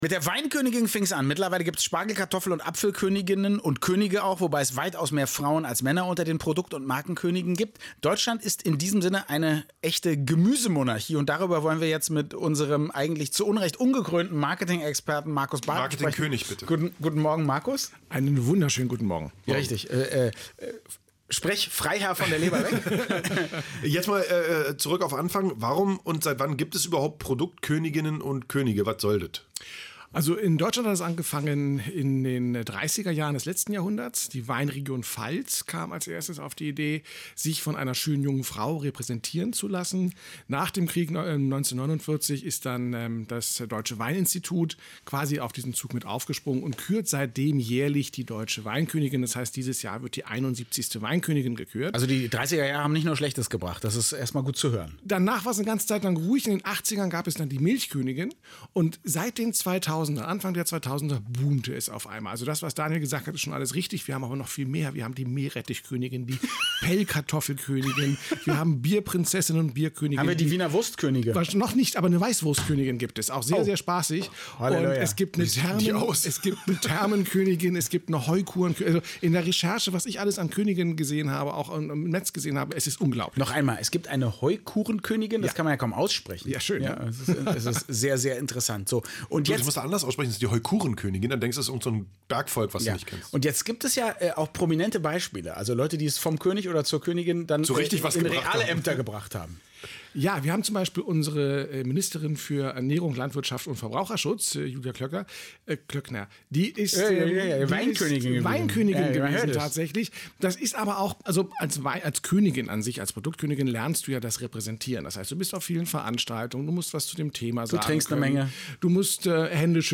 Doch dem Marketör ist nichts zu schwör und deshalb begab ich mich in das „radioeins„-Studio um über Königinnen zu sprechen: